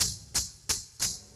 Closed Hats
Ety_pattern.wav